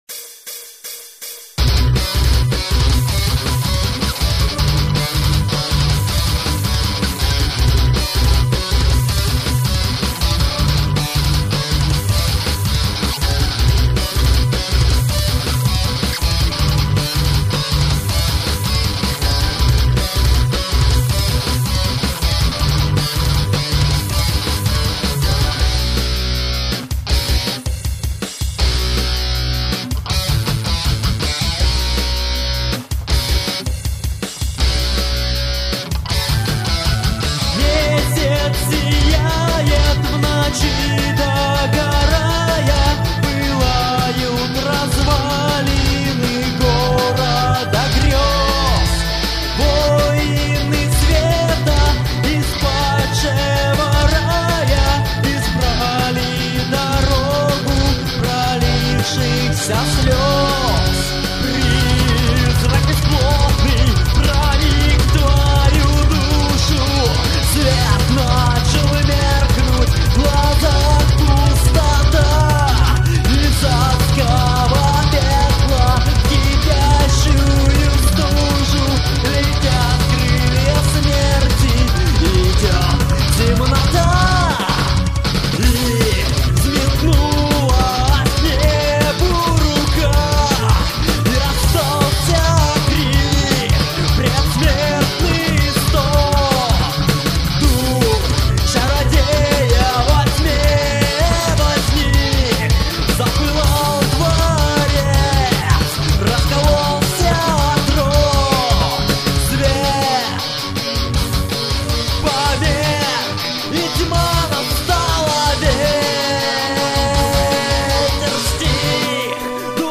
À ÷òî, åñëè êîãäà òû èäåøü íà âûñîêóþ íîòó â êîíöå êóïëåòà èëè ïðåïåâà, ïåðåéòè íà ôàëüöåò?
Åùå íà 3:30 äî ìîìåíòà ñîëî ÿ áû âûâåë ãèòàðû ÷óòü ïîãðîì÷å, à ïîòîì åùå ÷óòü ïîãðîì÷å áàñ, íî ÿ íå ïðîôè òàê ÷òî ýòî ìíåíèå äåëèòàíòà)).
À òàê, ðåàëüíî õîðîøî çâó÷èò.